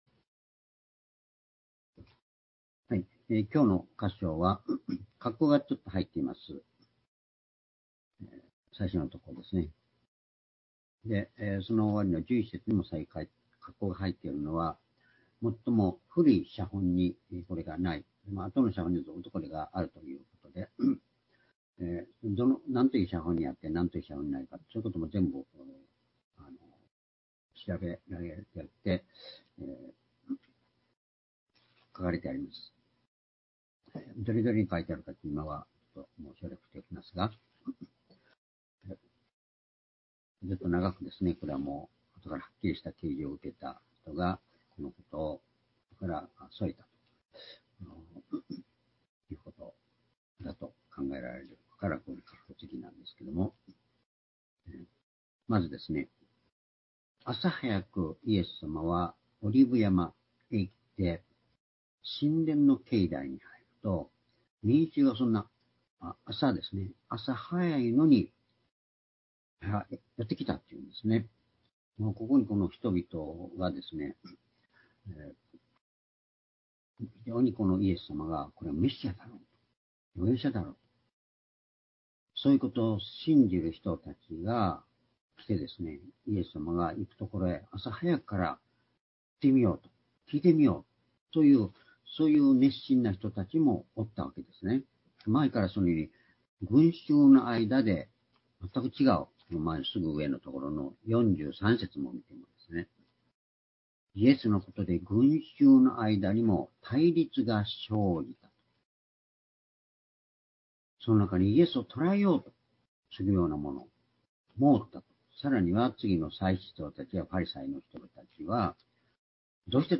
「イエスのまなざしとその力」ヨハネ8章１～11節-2023年1月29日(主日礼拝)